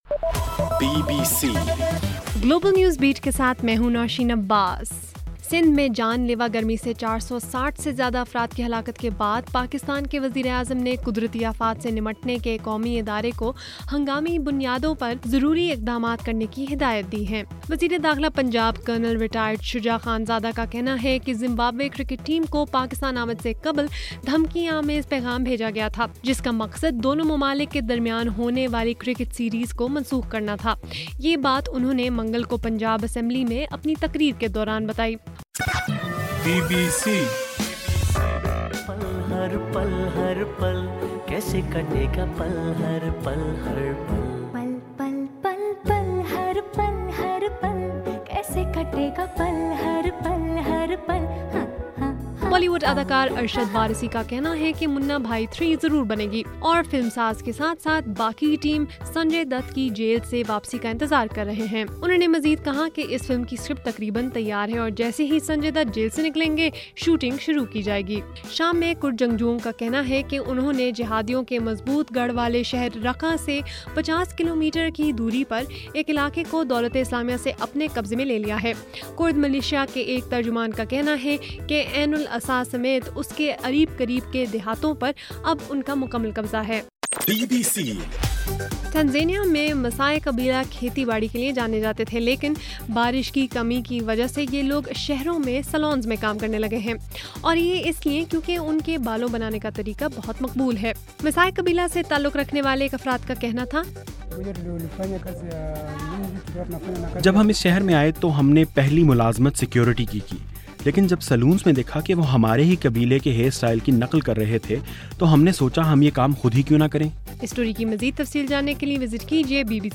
جون 23: رات 11 بجے کا گلوبل نیوز بیٹ بُلیٹن